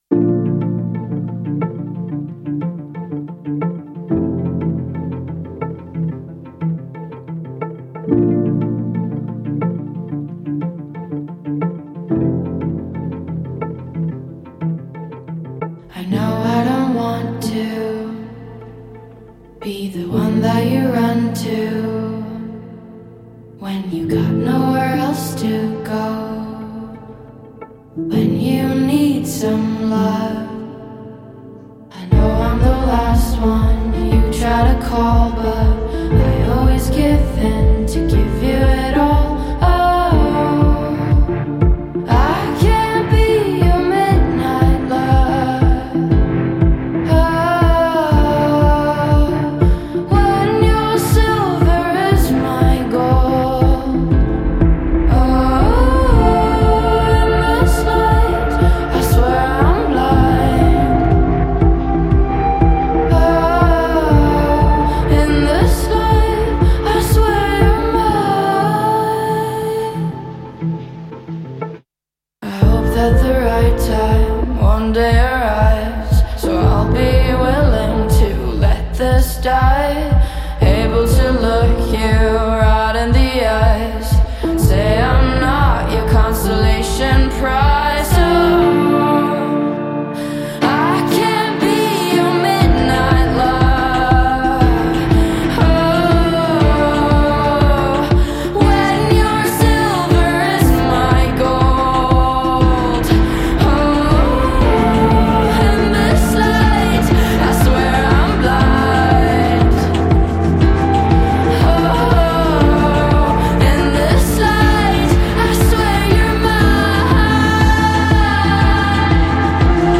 مود رویایی 🌠
موزیک خارجی